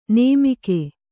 ♪ nēmike